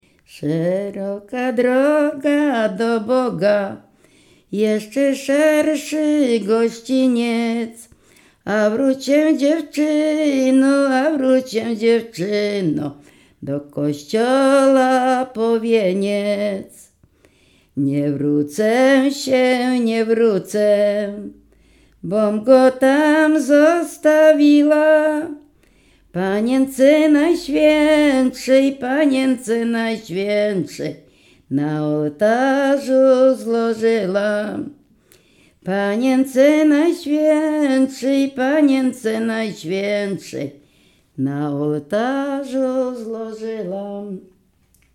województwo dolnośląskie, powiat lwówecki, gmina Mirsk, wieś Mroczkowice
W wymowie Ł wymawiane jako przedniojęzykowo-zębowe;
e (é) w końcu wyrazu zachowało jego dawną realizację jako i(y)
Weselna